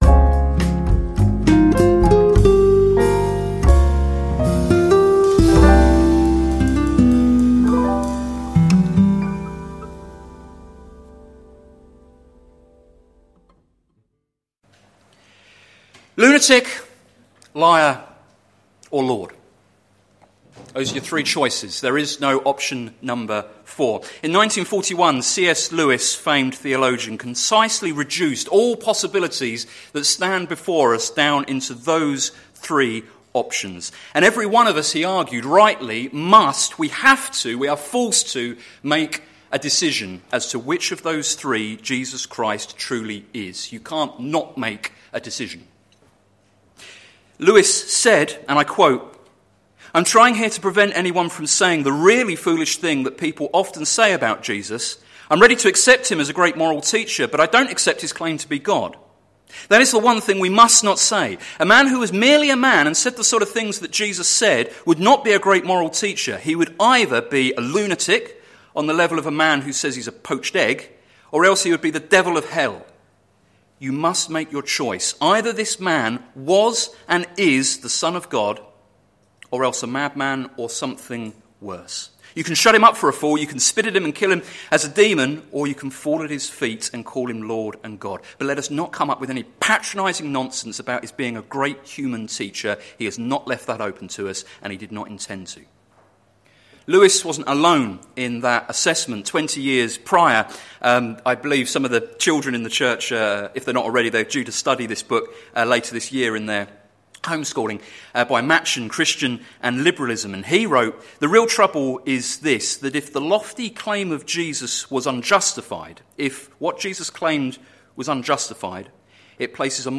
Sermon Series - Things Jesus said - plfc (Pound Lane Free Church, Isleham, Cambridgeshire)